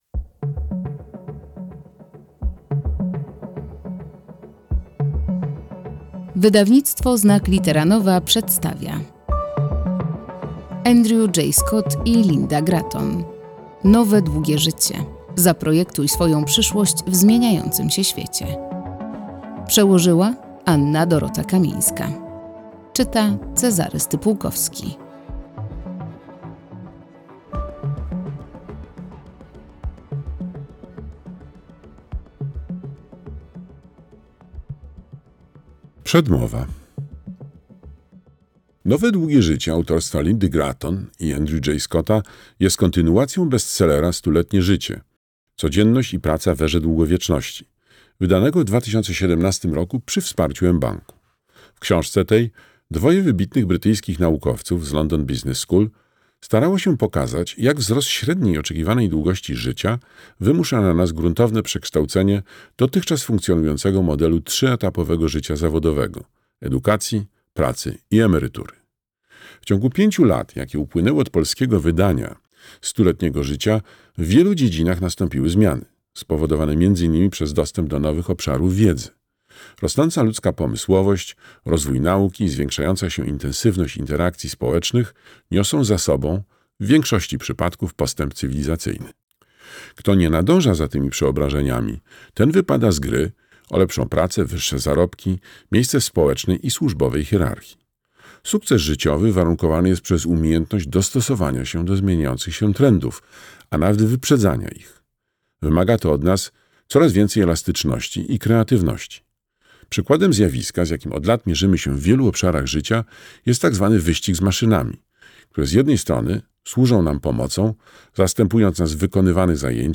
Zaprojektuj swoją przyszłość w zmieniającym się świecie - Andrew J. Scott, Lynda Gratton - audiobook